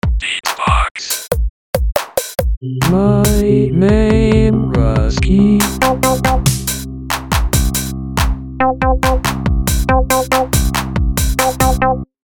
Так бред в FL сделал))) - Форум российского битбокс портала
Форум российского битбокс портала » Реорганизация форума - РЕСТАВРАЦИЯ » Выкладываем видео / аудио с битбоксом » Так бред в FL сделал)))